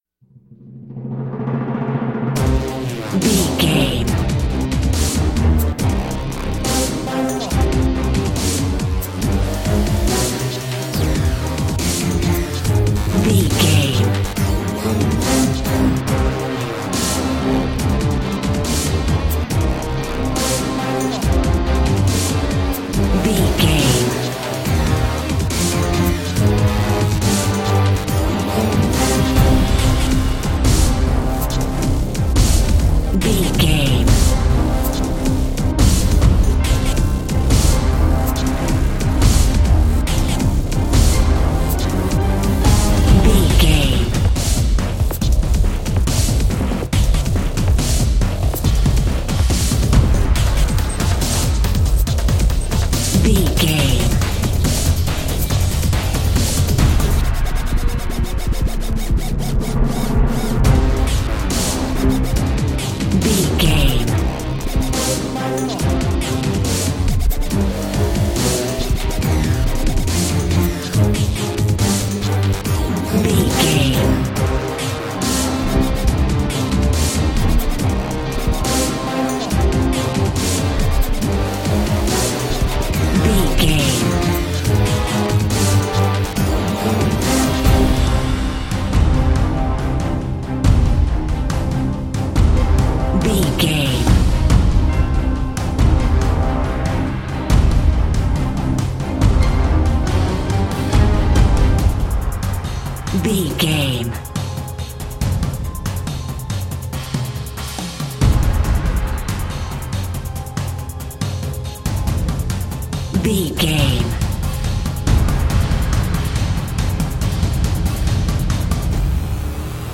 Aeolian/Minor
strings
drum machine
synthesiser
brass
orchestral
orchestral hybrid
dubstep
aggressive
energetic
intense
synth effects
wobbles
driving drum beat
epic